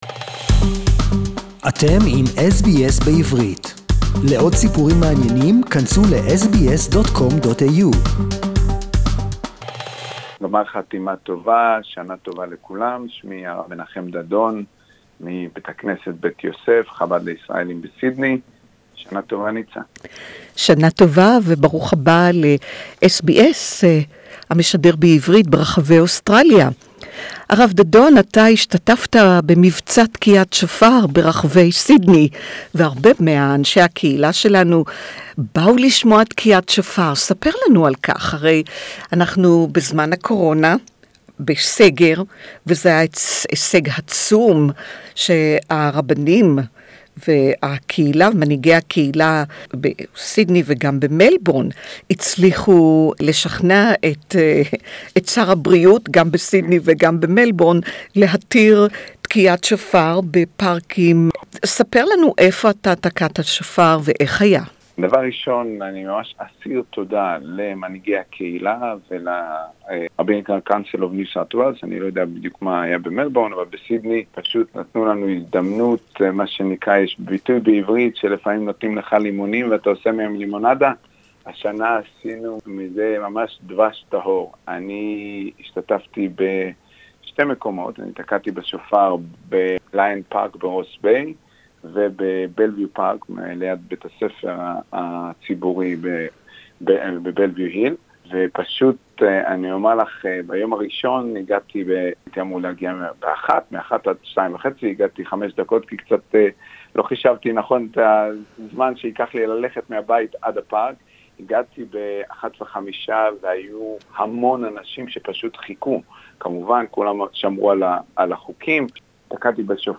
We will talk to him about this exciting unique experience and we will find out the challenges of observing Yom Kippur under lockdown Interview in Hebrew Share